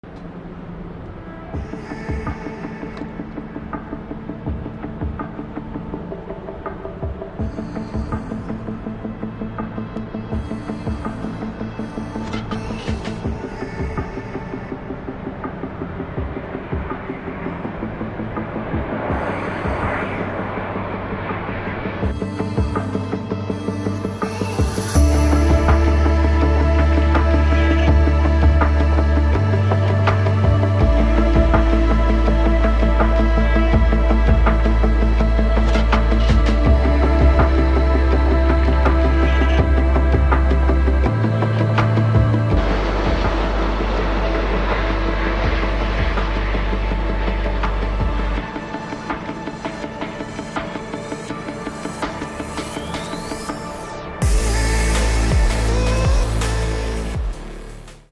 Sloq Motion A380 Landing Heathrow